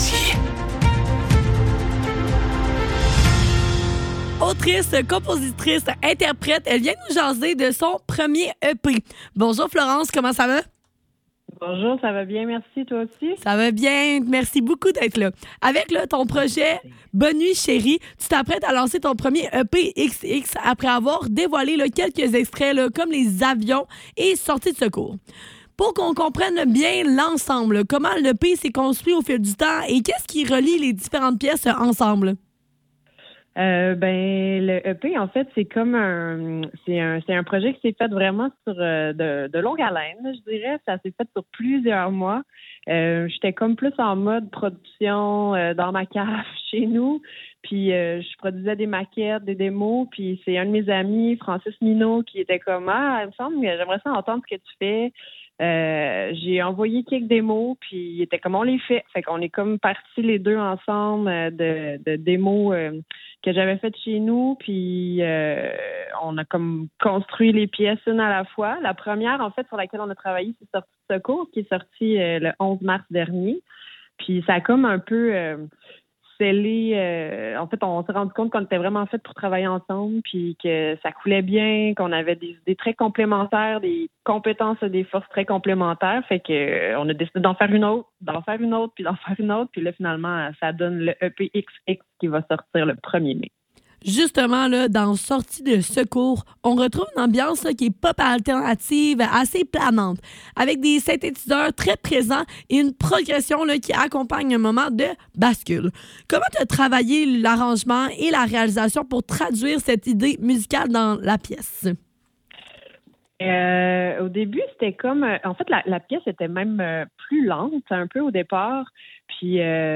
Le Neuf - Entrevue avec Bonne nuit, Chérie - 2 avril 2026